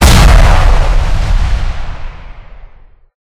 explosion.ogg